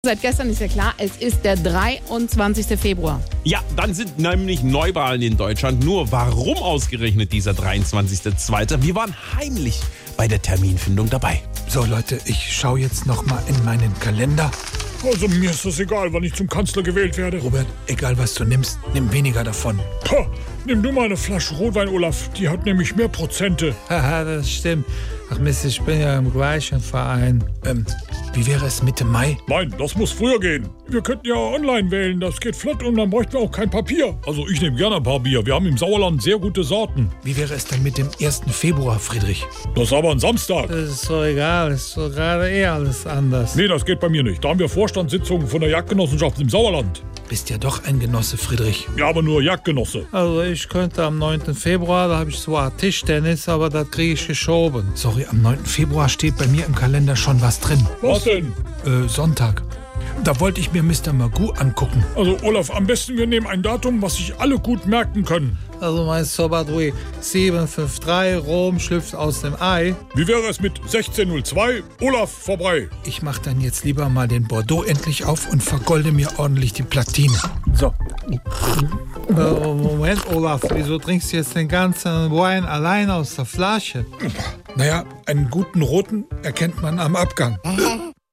SWR3 Comedy Terminfindung